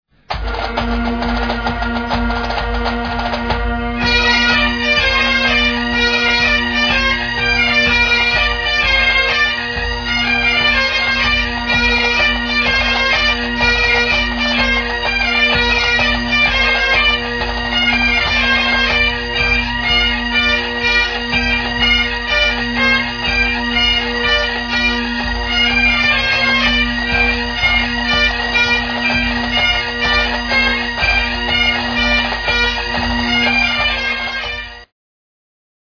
Pipes & Drums
Audio samples are low resolution for browsing speed.
4/4 / Unlisted
Original Recordings:  Audio Arts Studio, Johannesburg 1984